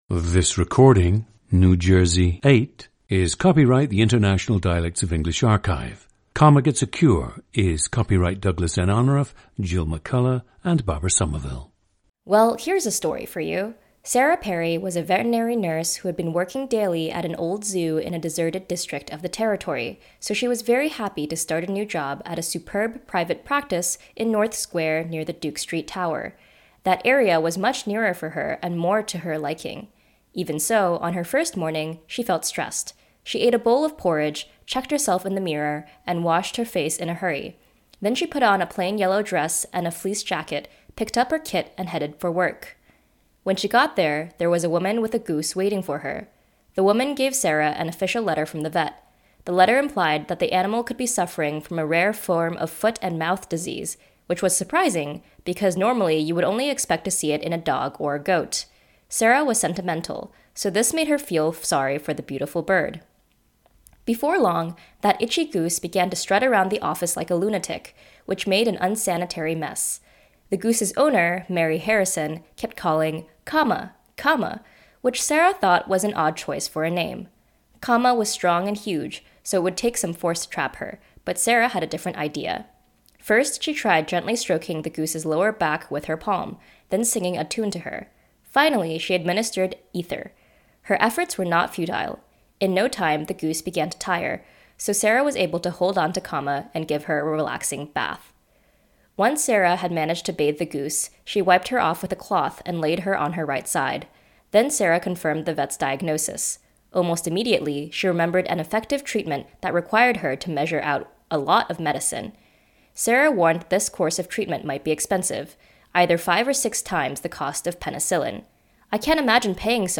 Listen to New Jersey 8, a 24-year-old woman from Somerset County, New Jersey, United States.
GENDER: female
Both her parents spoke English with Chinese accents, and she was encouraged to speak Mandarin at home.